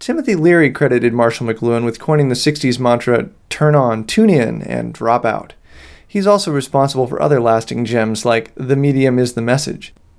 I unfortunately had to record different pieces of the lecture in two different rooms and the ambience of the resulting recordings differs somewhat.
To be clear, the recordings aren’t of a live lecture recorded in front of people, but rather me sitting in a quiet space in front of a mic.
Your recordings at the different locations are stereo, which gives-away they were made in different places.